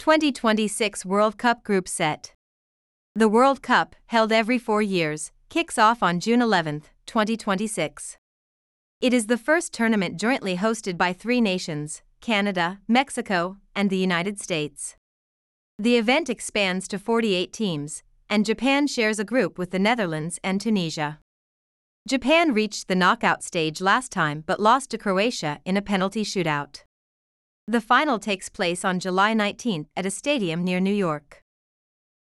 【ナチュラルスピード】